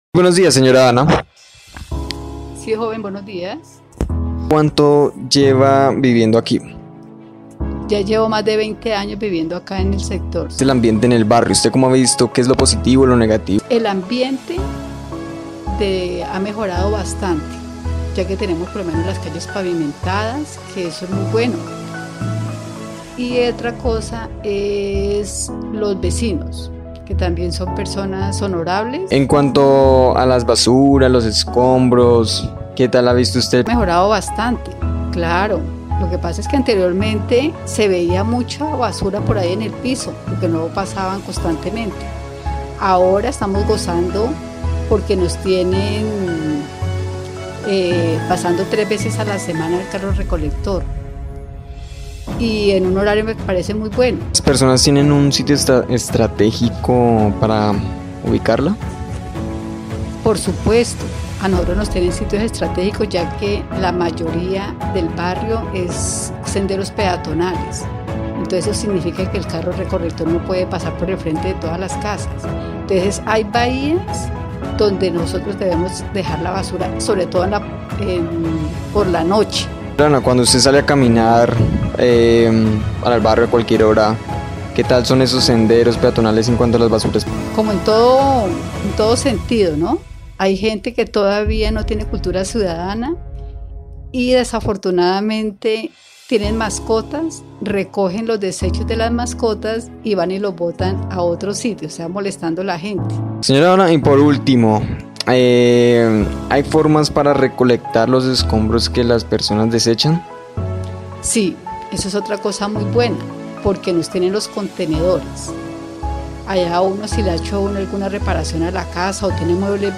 Ambiente_-Entrevista.mp3